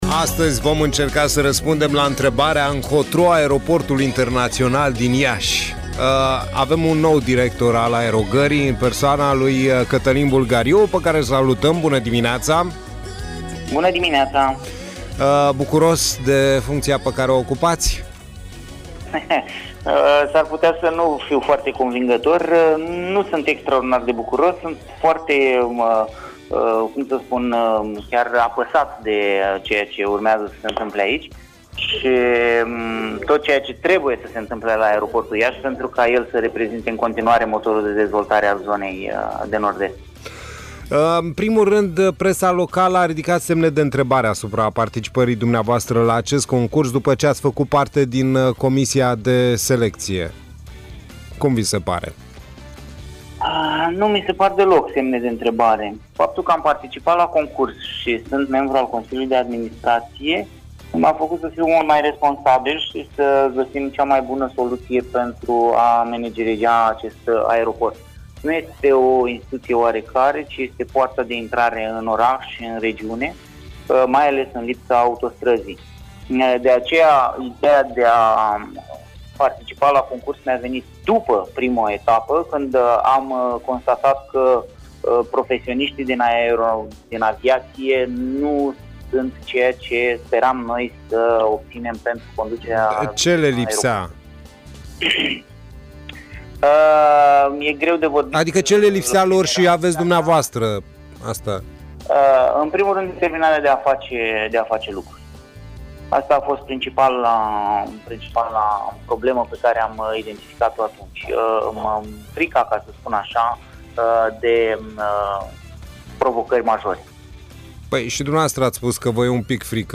a acordat un interviu la HIT.